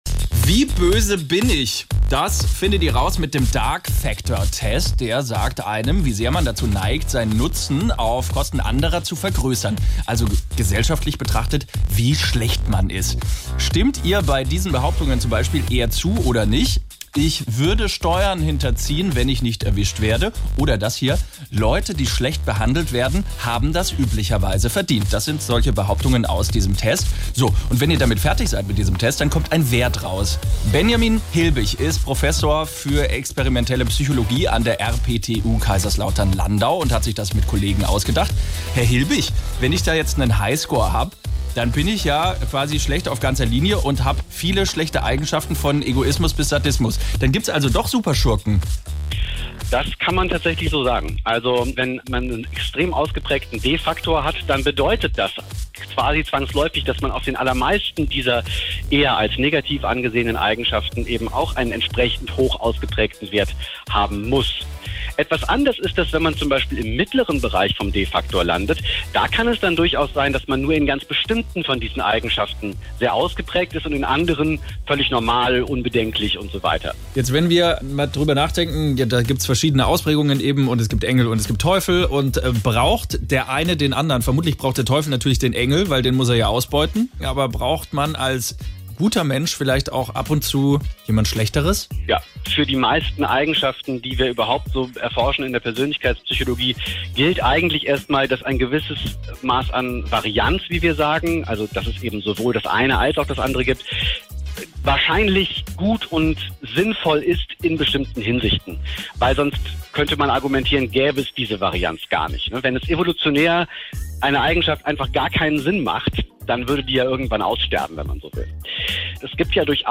Nachrichten SWR3-Interview mit den Wissenschaftlern des Dark-Faktor-Tests